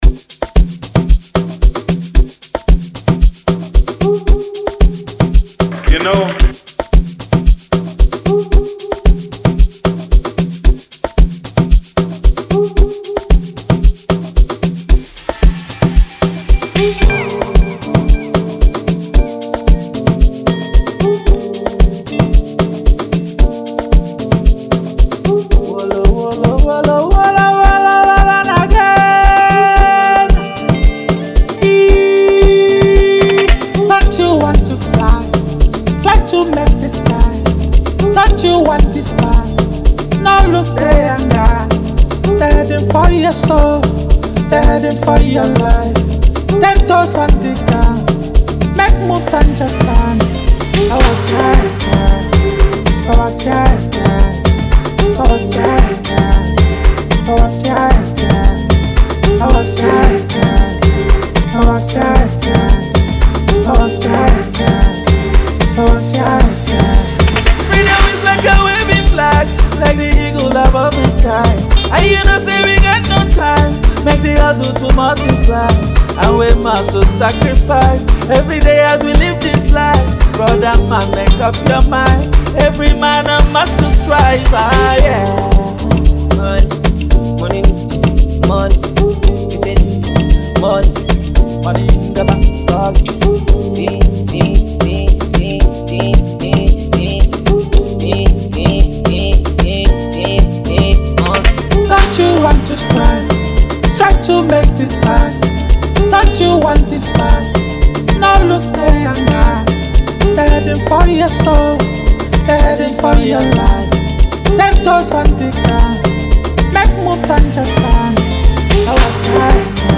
Nigerian Top Notch singer-songwriter and talented artist
With a captivating melody and enchanting lyrics